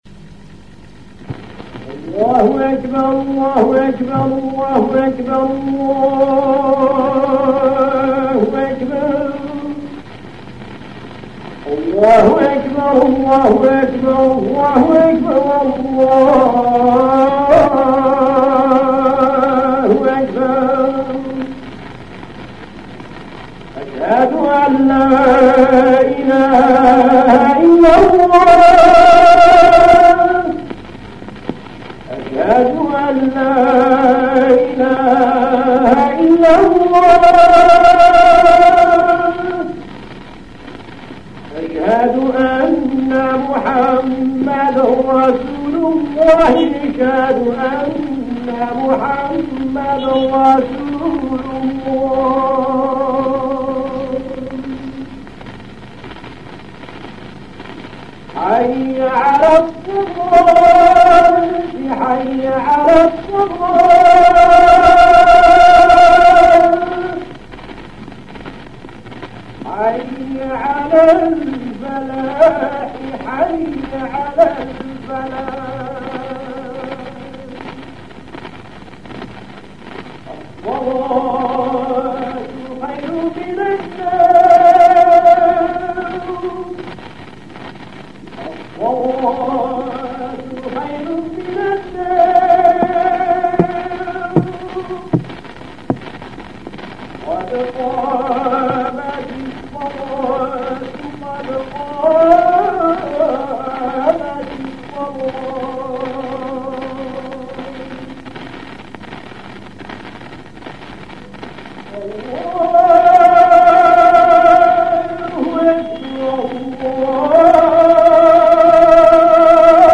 La Pratique de l'ADHAN
A Alger, les appels à la prière se faisaient presque tous sur le mode Zidane (quelle que soit la prière : Fadjr, Dôhr ou même Îch’a).
L’annonce du début de la prière peut se faire sur n’importe quel mode, tout dépend de l’humeur et de l’état d’âme de la personne.
iqama_02.mp3